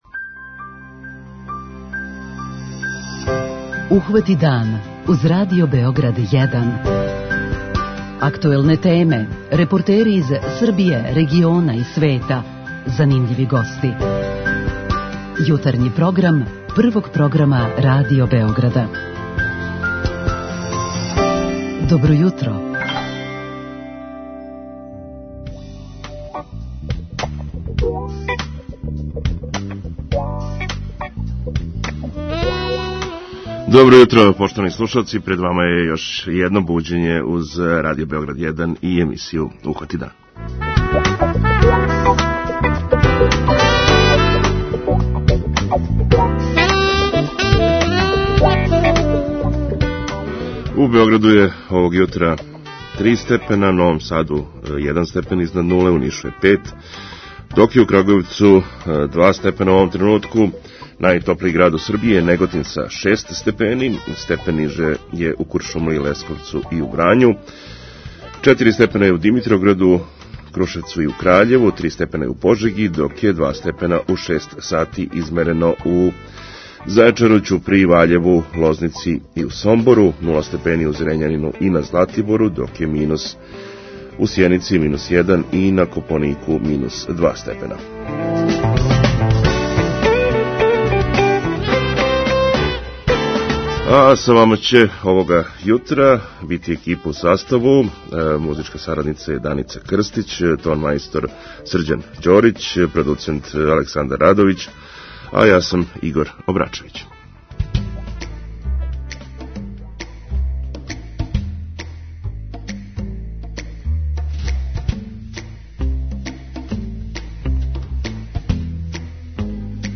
преузми : 37.78 MB Ухвати дан Autor: Група аутора Јутарњи програм Радио Београда 1!